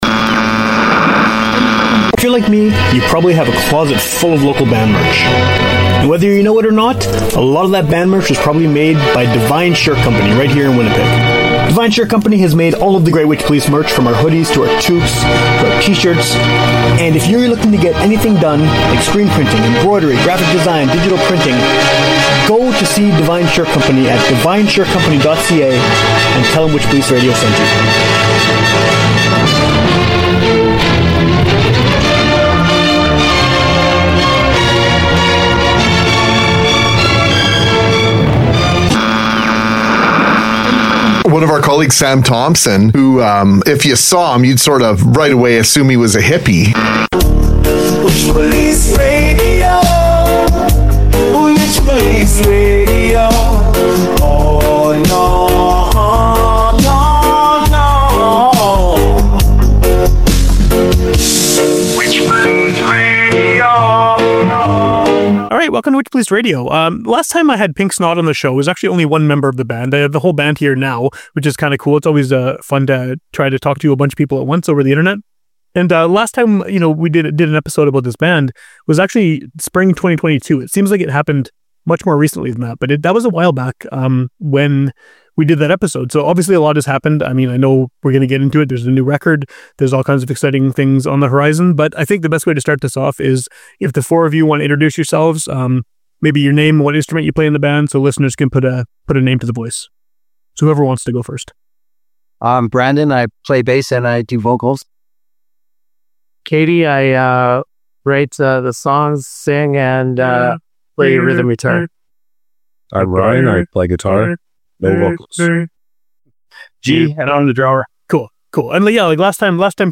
Note: As sometimes happens when interviewing a group of people (in the same room) remotely, there are some weird echo things that happen occasionally on this one, where it may sound like one of the band members' voices has temporarily doubled.